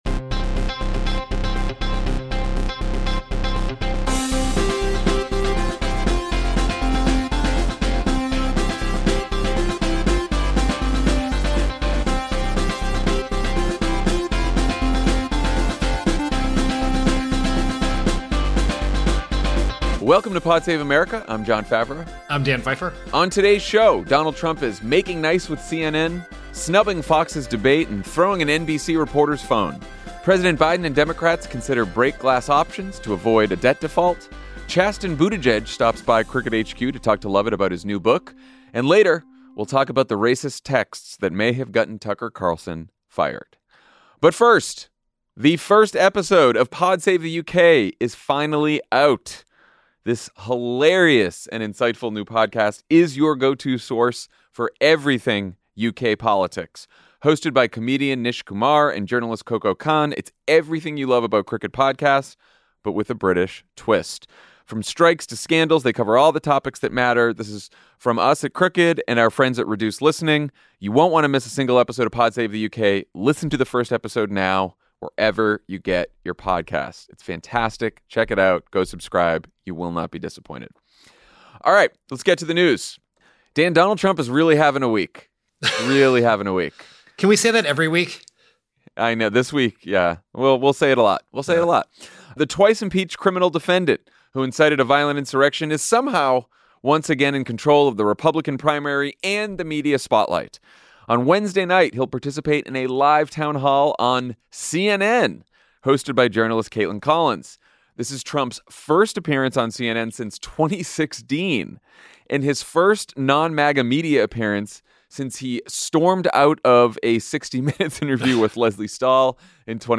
Donald Trump is making nice with CNN, snubbing Fox’s debate, and throwing an NBC reporter’s phone. President Biden and Democrats consider break glass options to avoid a debt default. Chasten Buttigieg chats with Lovett about his new book.